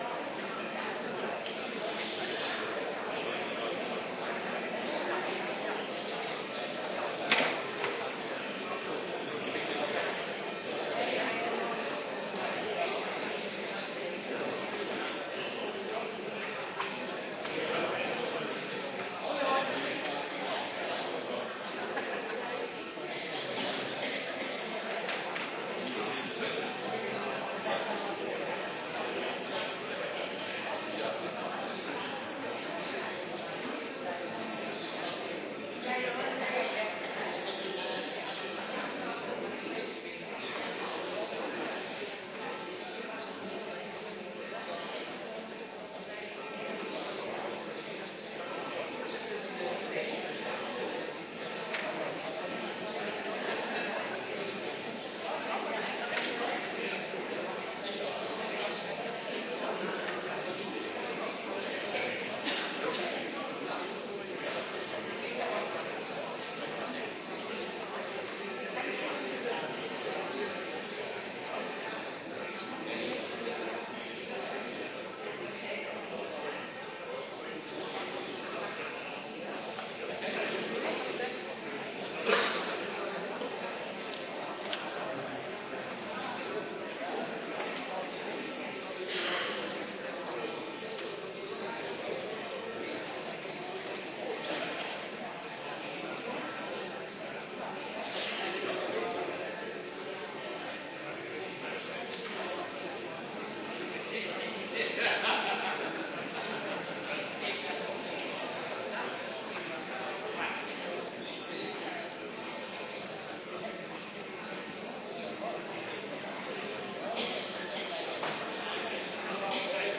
Adventkerk Zondag week 25
* Viering Heilig Avondmaal Voorganger